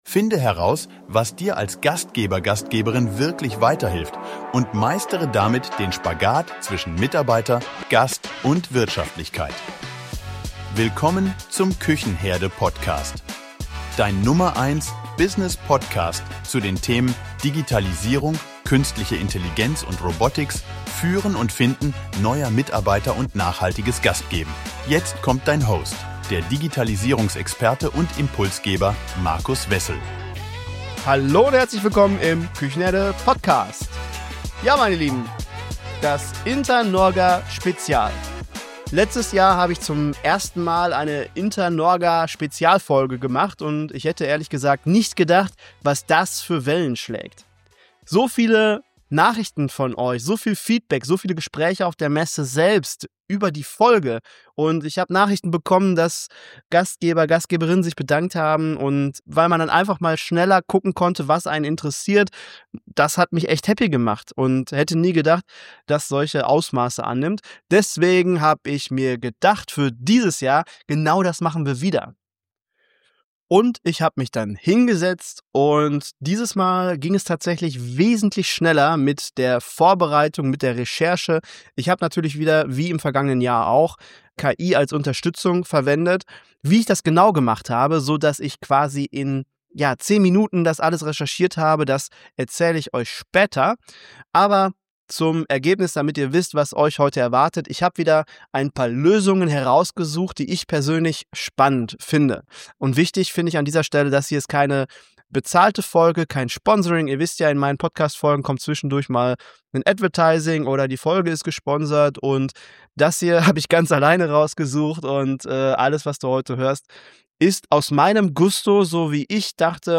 Mit im Interview waren: